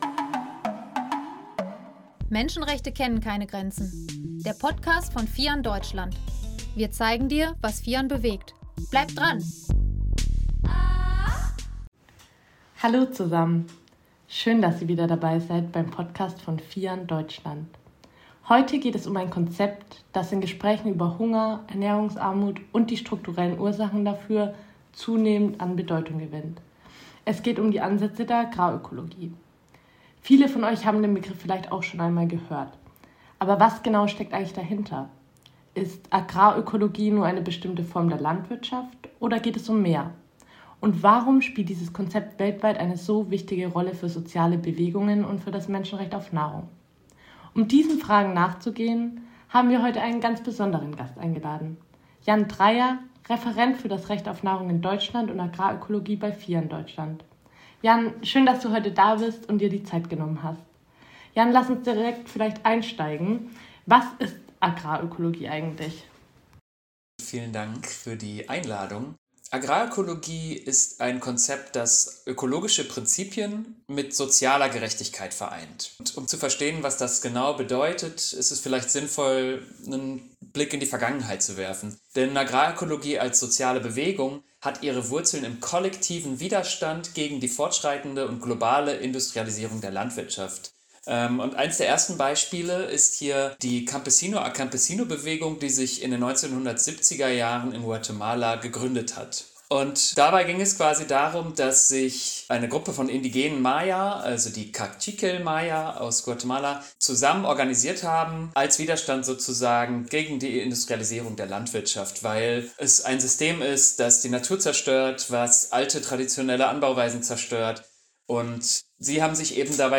Interviewgast